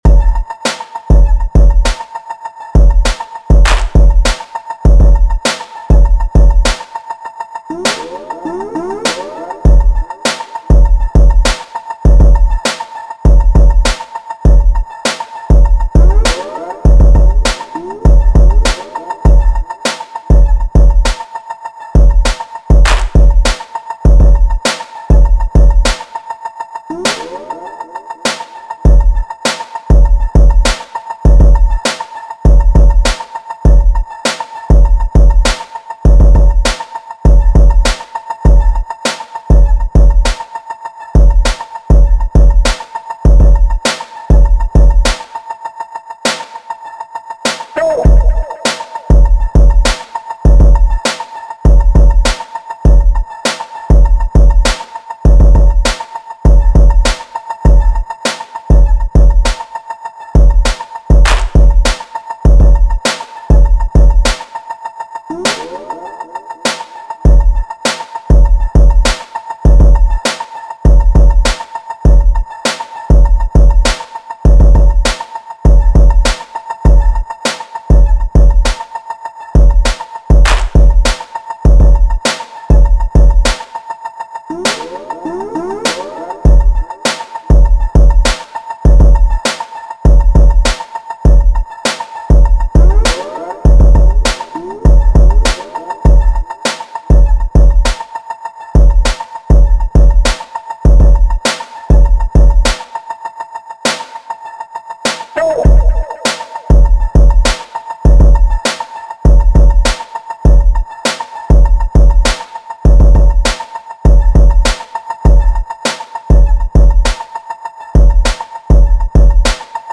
Latest Skratch Beats!
100 bpm